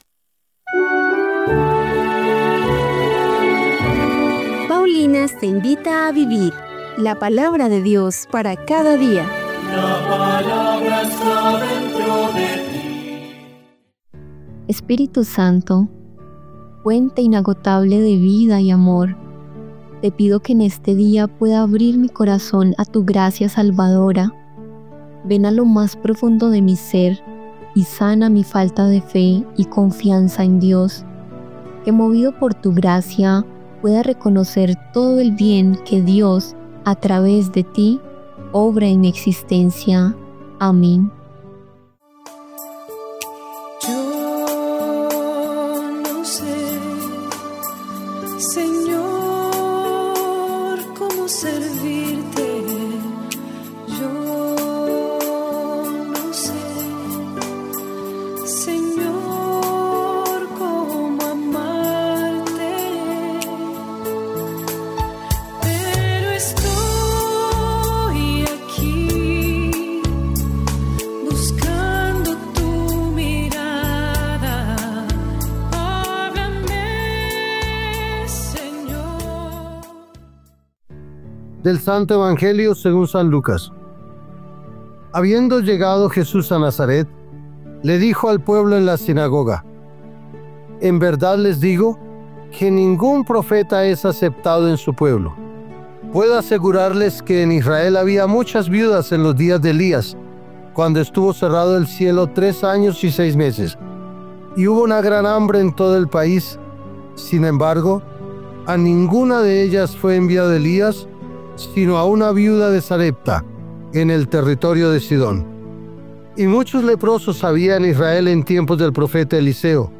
Lectura del libro de Isaías 50, 4-7